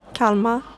Kalmar (/ˈkælmɑːr/, US also /ˈkɑːlmɑːr/,[2][3] Swedish: [ˈkǎlmar]